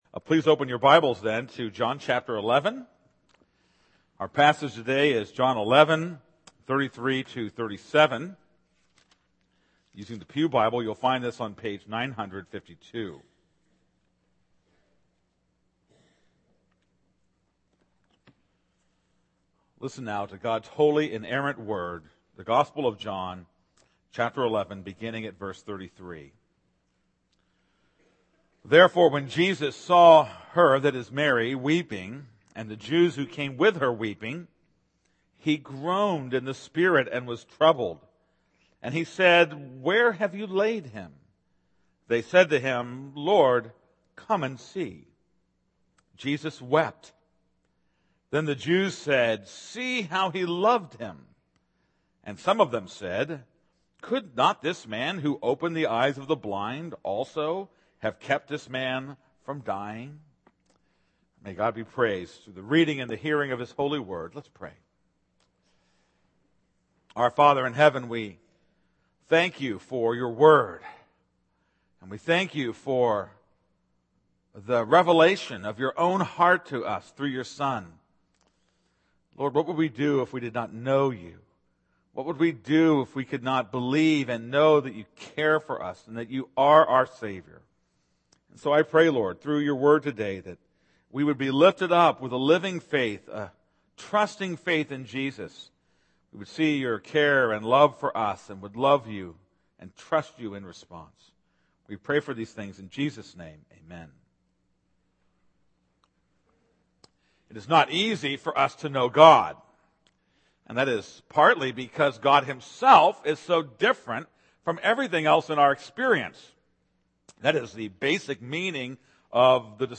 This is a sermon on John 11:33-37.